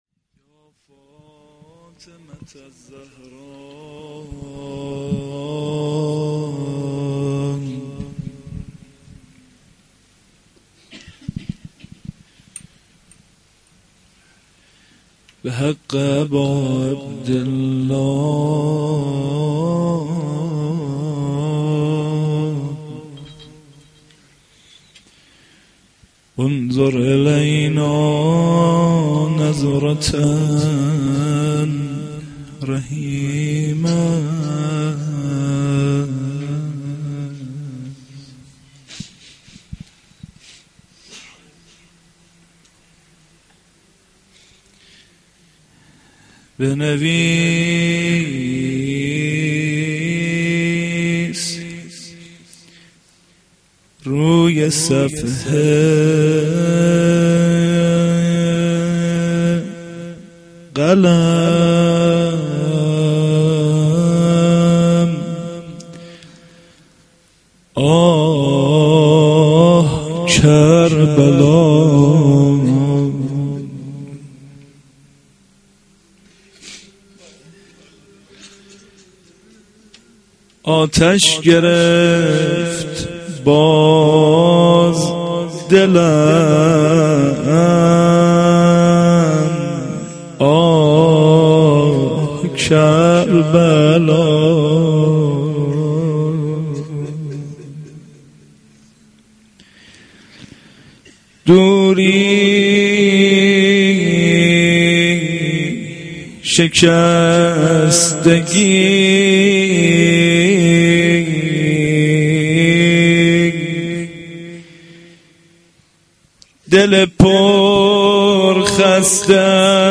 sh-2-moharram-92-roza.mp3